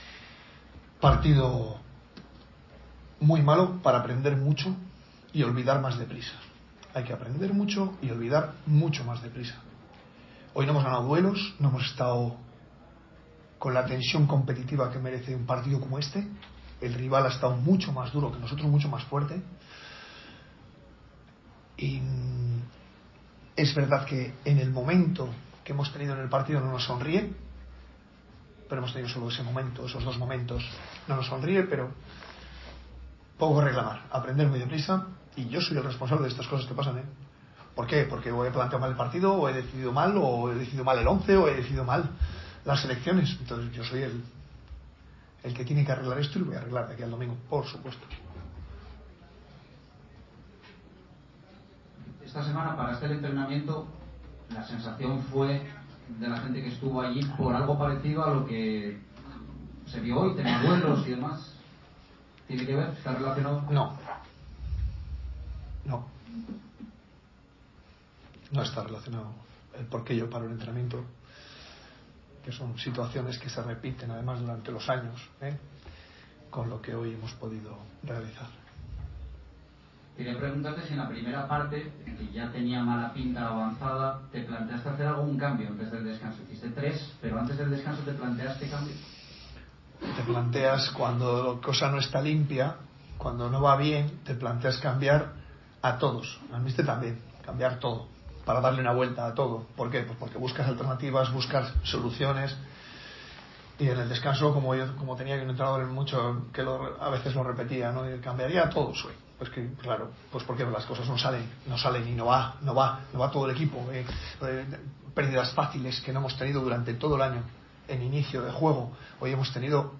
"Un partido muy malo, para aprender mucho y olvidar más deprisa" condensó el técnico blanquivioleta en la rueda de prensa posterior al 3-0.